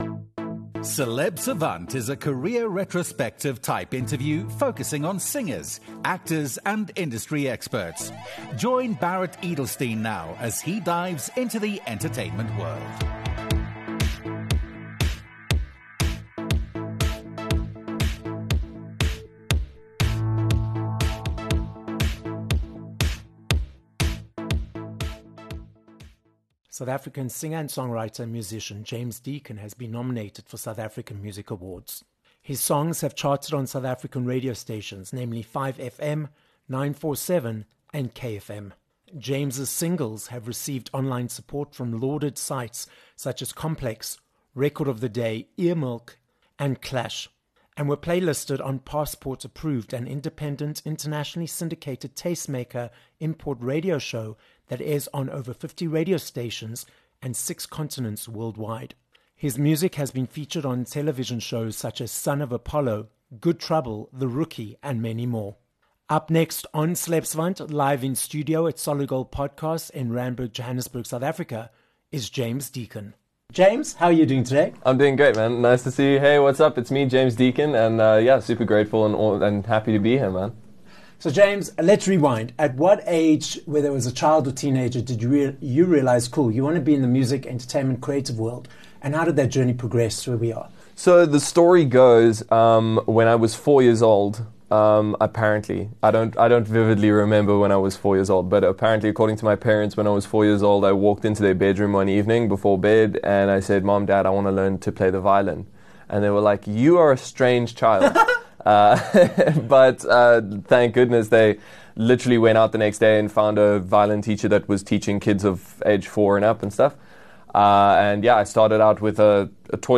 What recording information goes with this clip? This episode of Celeb Savant was recorded live at Solid Gold Podcasts, Johannesburg, South Africa.